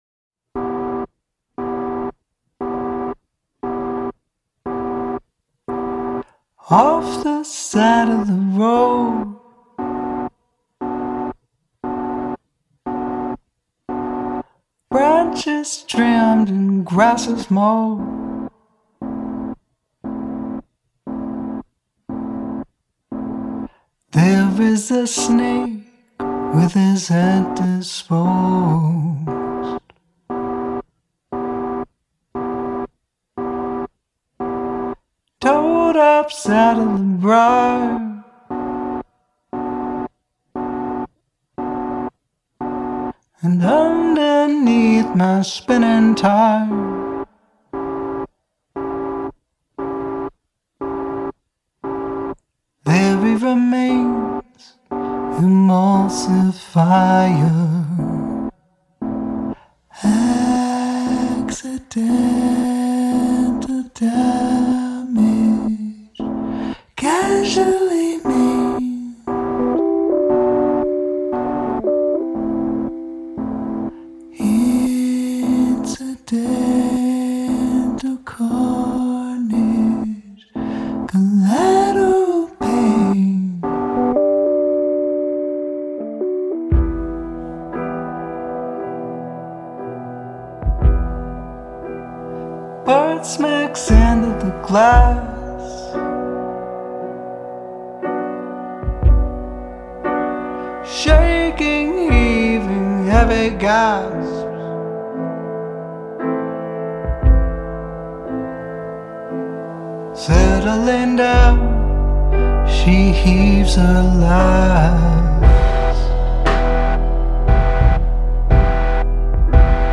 жанры  Indie, Indie Rock, Indie Folk, Art Rock, Dream Pop